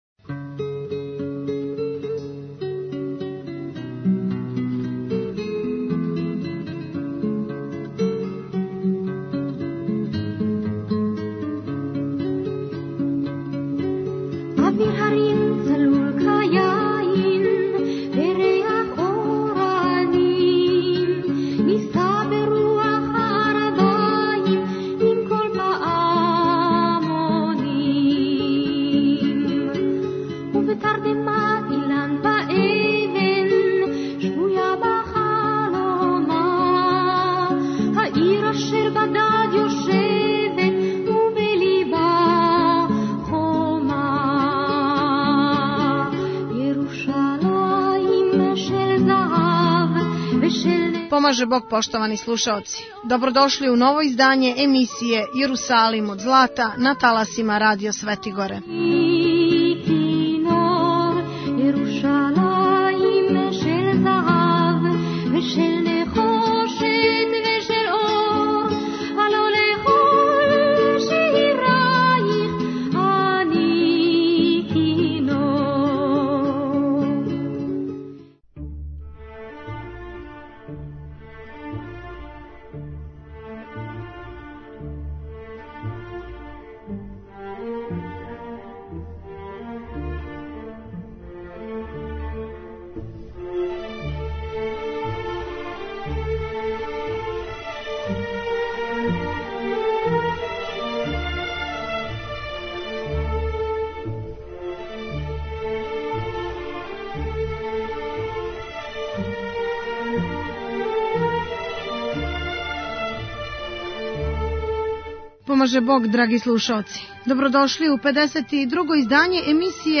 Кроз данашњу емисију осврнућемо се на Семинар о образовању о холокаусту који је одржан у просторијама Јеврејске заједнице у Београду у уторак, 2. фебруара 2010. године, коју је одржала делегација Међународне школе Јад Вашем из Јерусалима за потребе Одбора за Јасеновац.